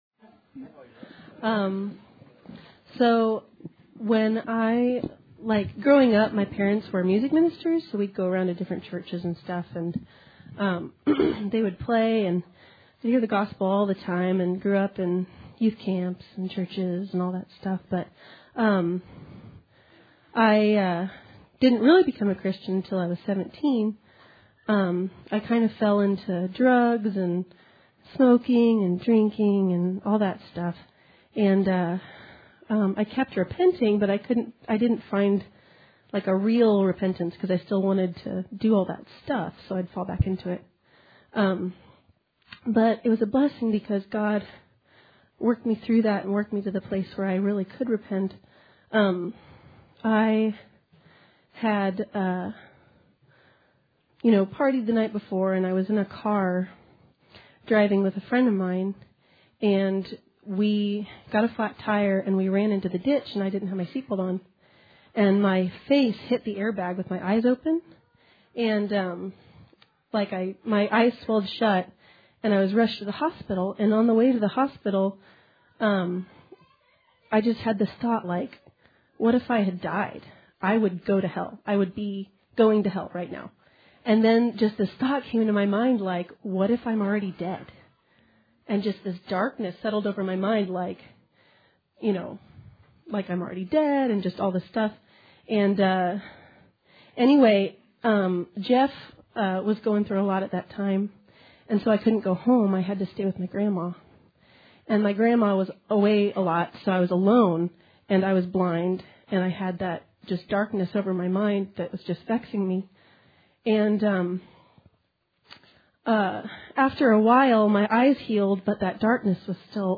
Sermon 5/29/16